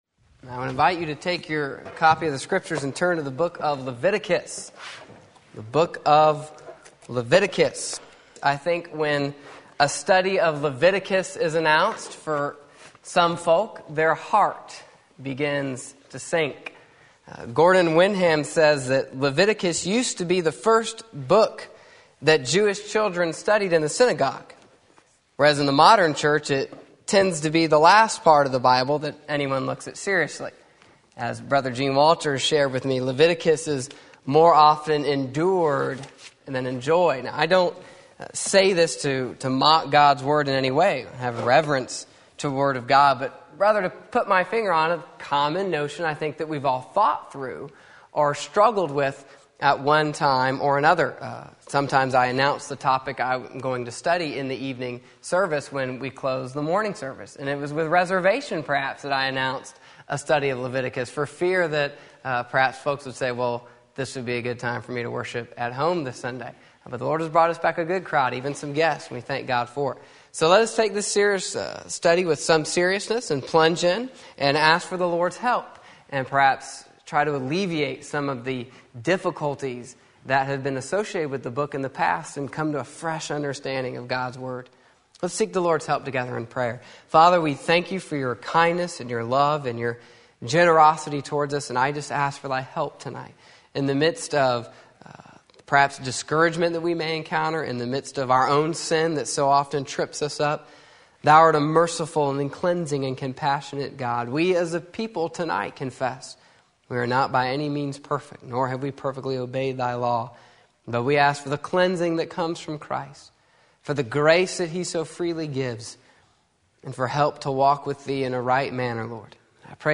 Sermon Link
A Book about Holiness Leviticus Sunday Evening Service